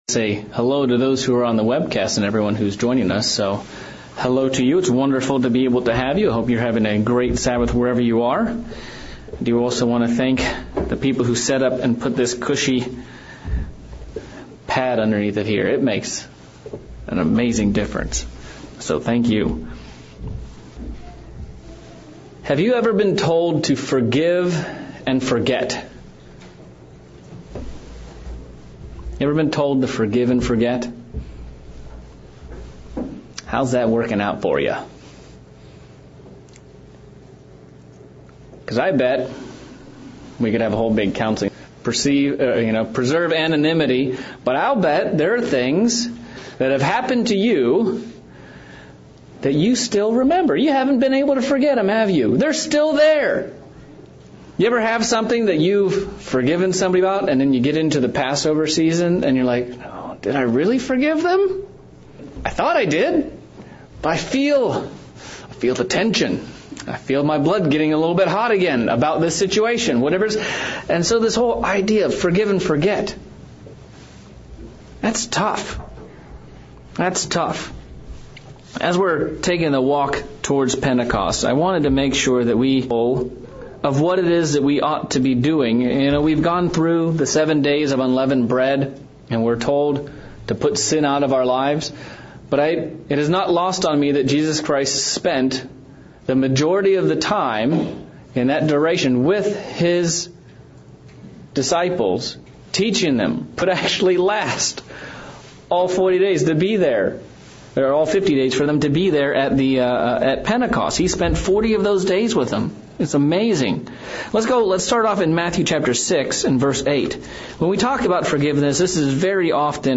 Part 1 of a 3 part sermon series on forgiveness, repentance, and reconciliation. What does it mean to forgive and to forget and is it humanly possible? How many times should I forgive my brother?